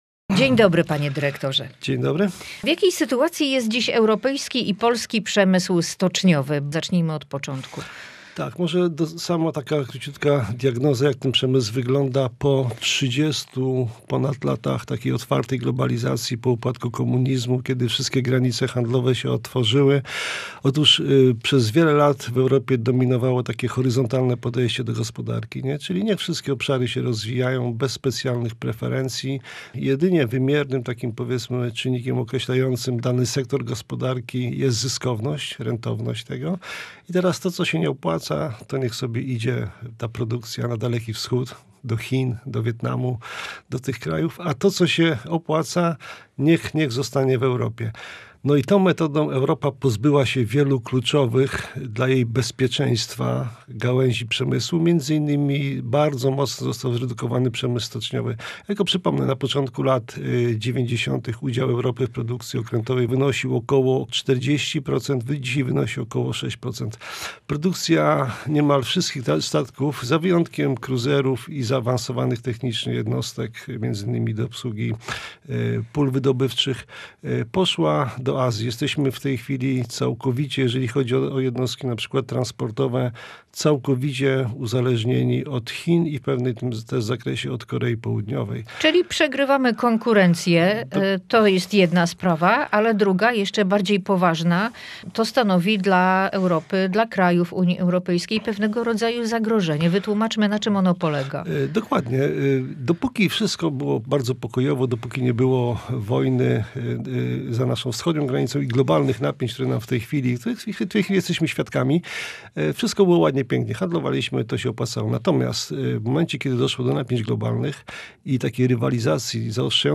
Globalizacja kontra bezpieczeństwo. Rozmowa o przyszłości stoczni w Polsce i Europie